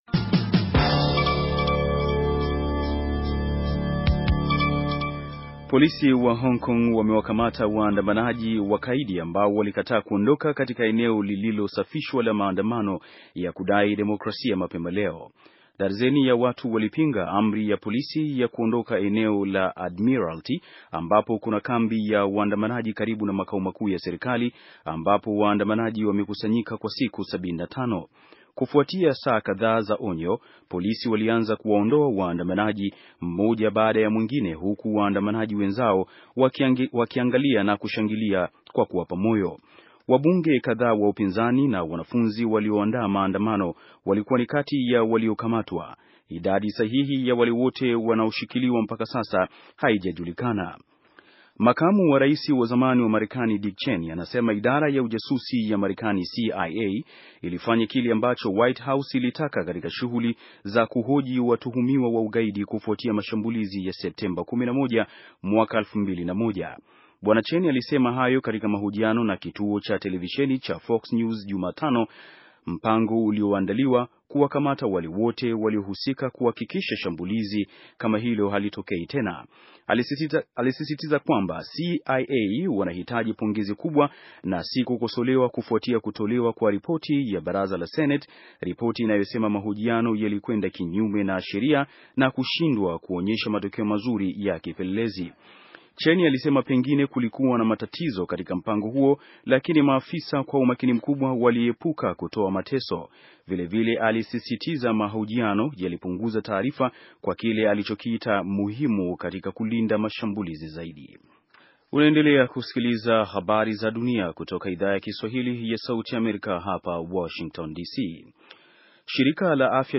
Taarifa ya habari - 6:29